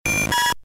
Cri de Chenipan K.O. dans Pokémon Diamant et Perle.